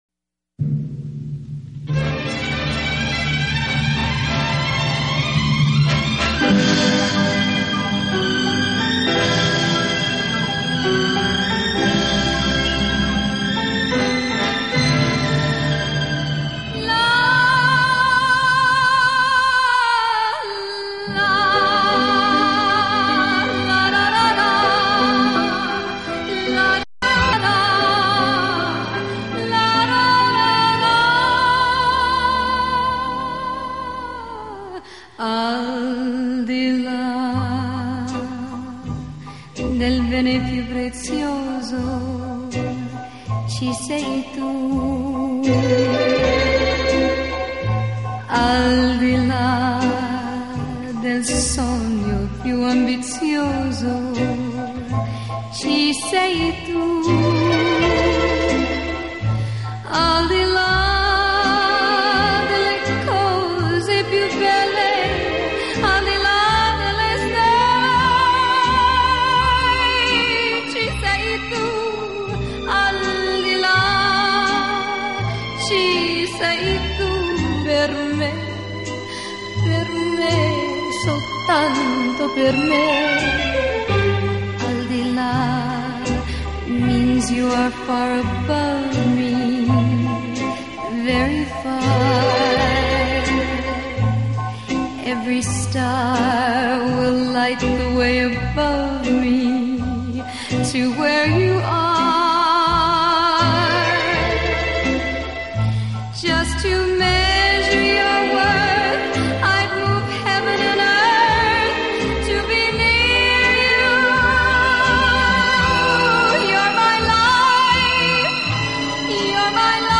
以英文和意大利文双语灌录